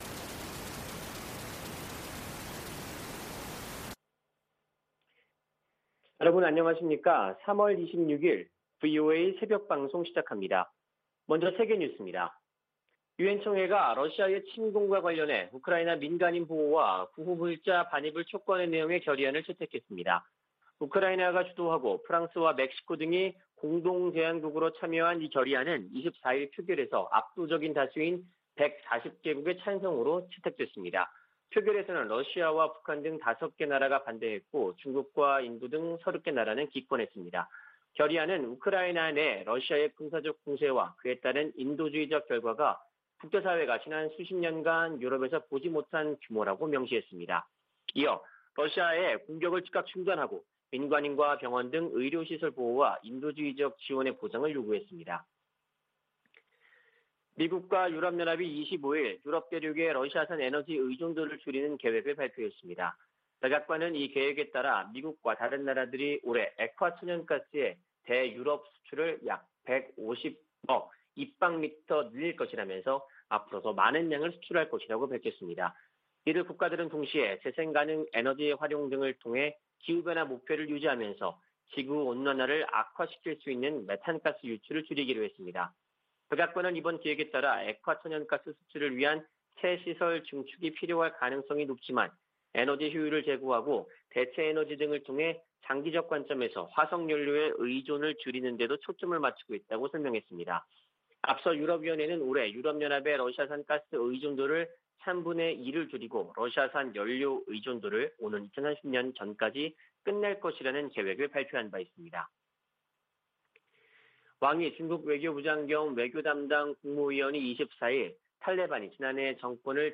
VOA 한국어 '출발 뉴스 쇼', 2022년 3월 26일 방송입니다. 북한은 24일 발사한 탄도미사일이 신형 ICBM인 '화성-17형'이라며 시험발사에 성공했다고 밝혔습니다. 미국 정부는 북한이 ICBM으로 추정되는 장거리 탄도미사일을 발사한 데 강력한 규탄 입장을 밝혔습니다. 미국 정부가 북한 미사일 프로그램에 연관된 북한과 러시아의 개인과 회사들을 제재했습니다.